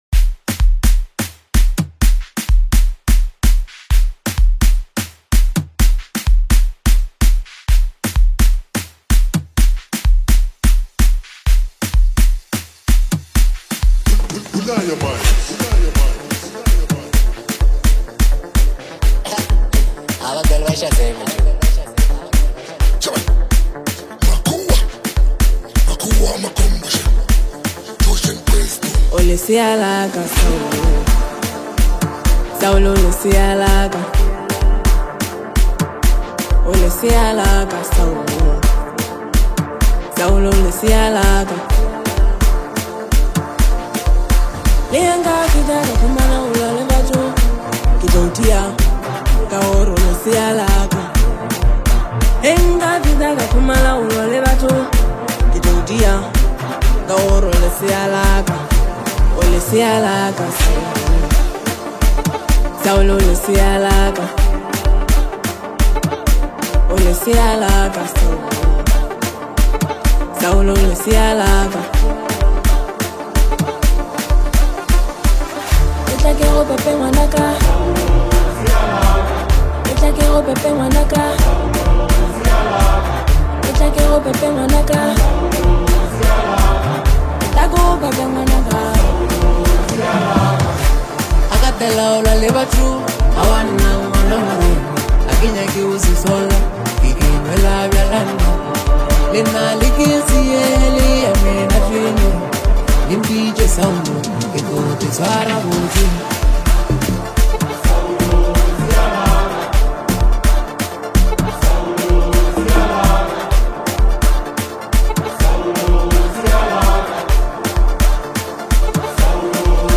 an atmosphere of warmth,intimacy and gentle celebration
lekompo love songs